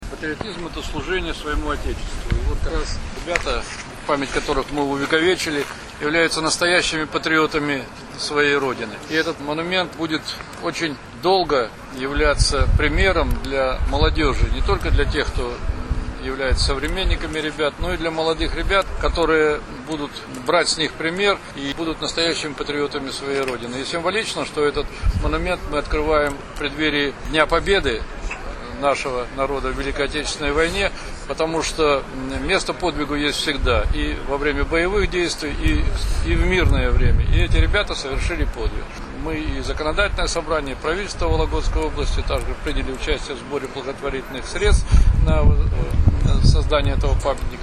Георгий Шевцов от открытии памятника погибшим сотрудникам МЧС